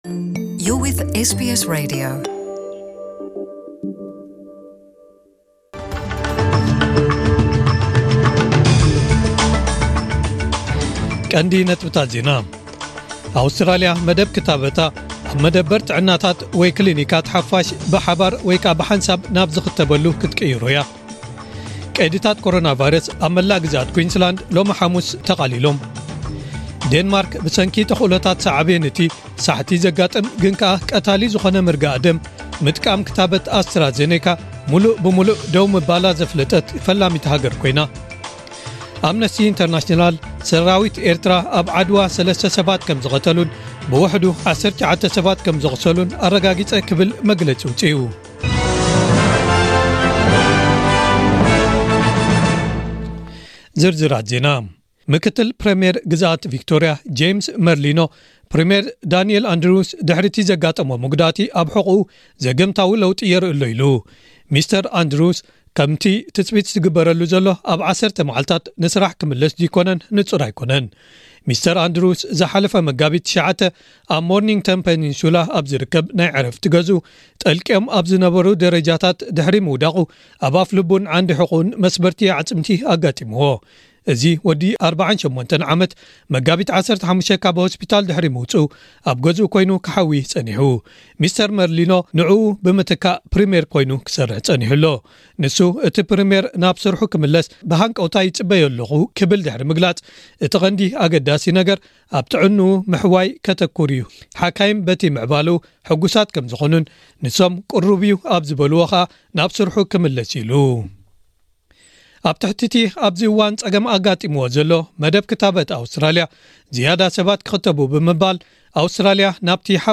ዕለታዊ ዜና ኤስቢኤስ ትግርኛ